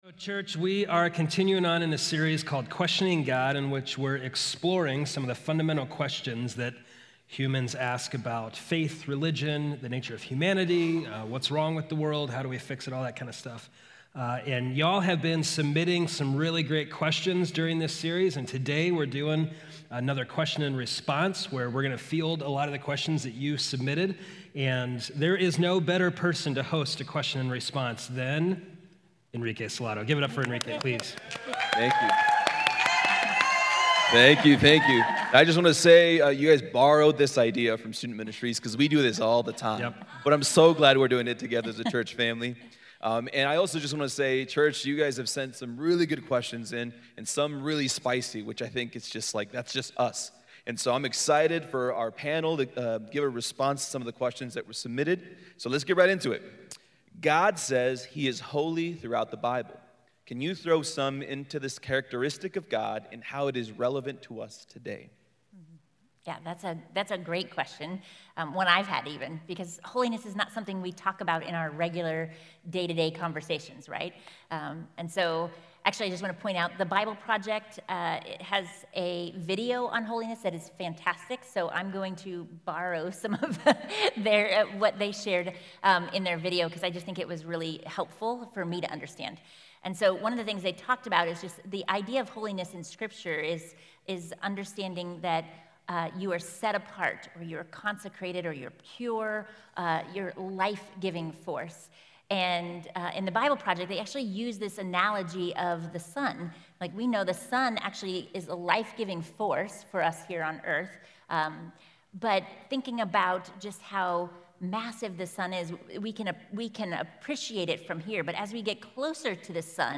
Q&A Service